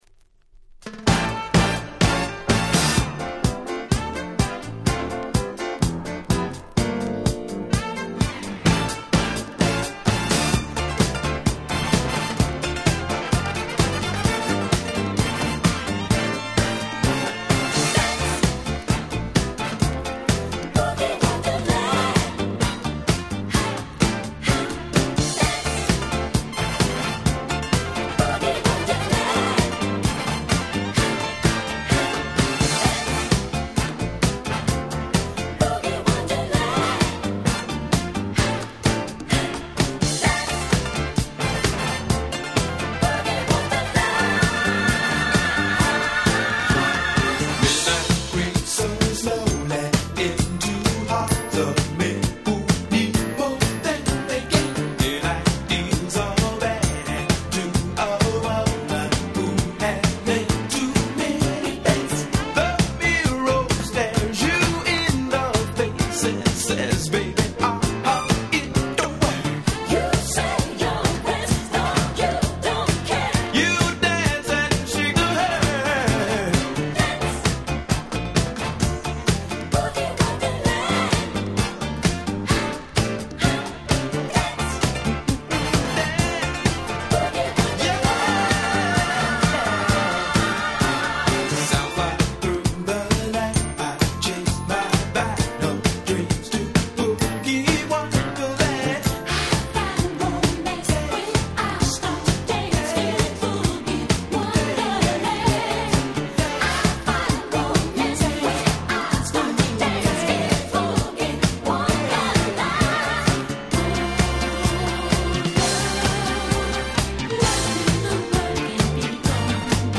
79' Super Hit Disco !!
Disco
Dance Classics